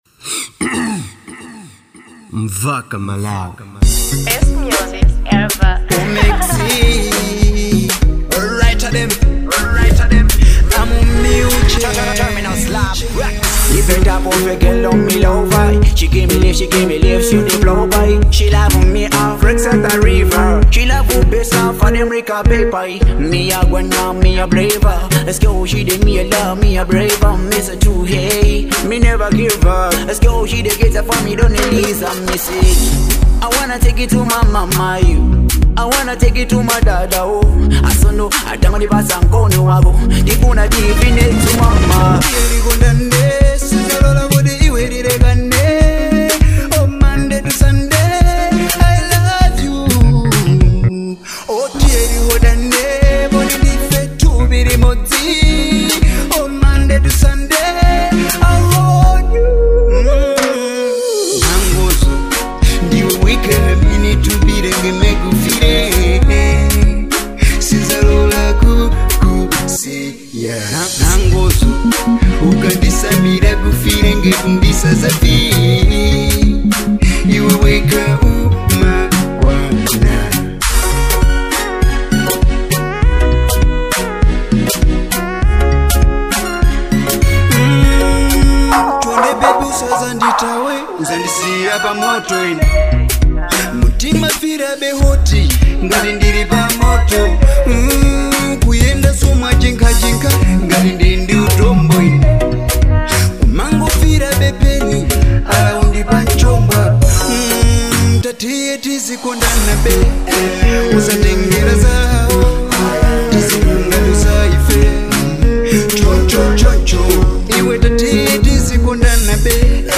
type:love song